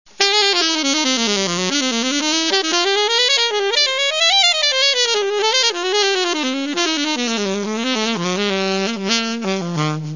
Saxofon contralt